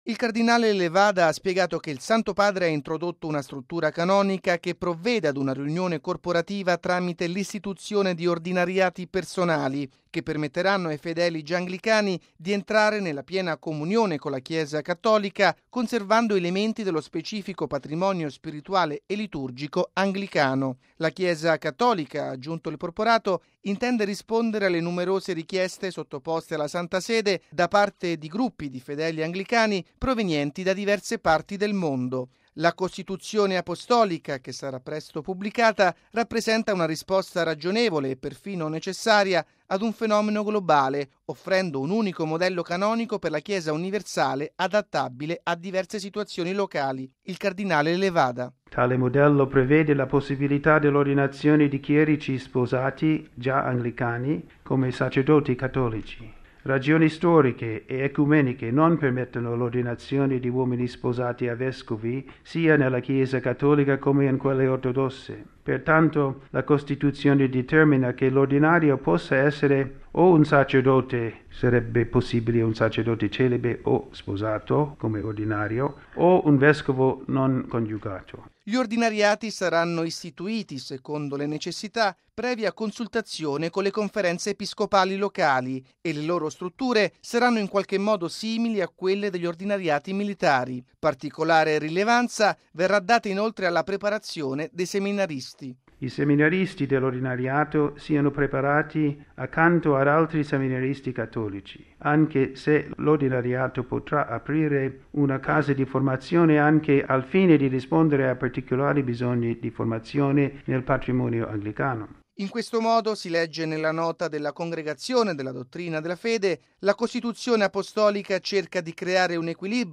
Il cardinale William Joseph Levada: